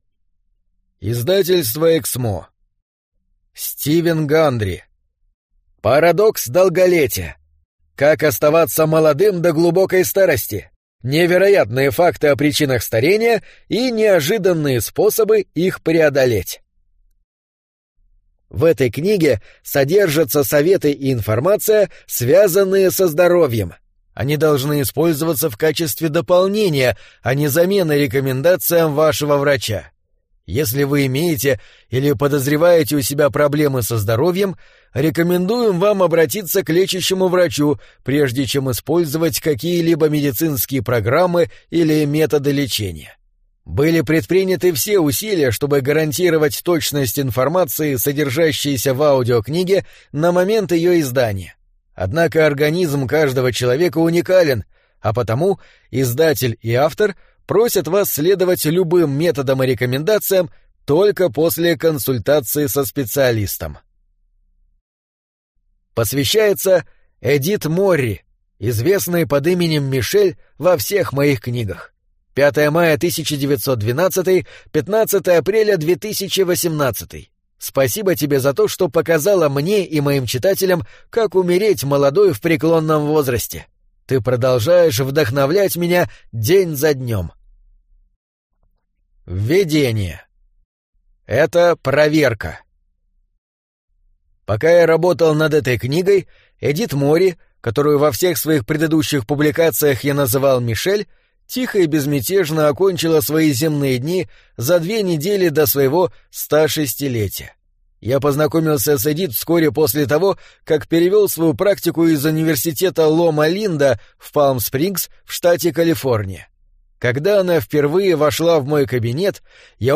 Аудиокнига Парадокс долголетия. Как оставаться молодым до глубокой старости | Библиотека аудиокниг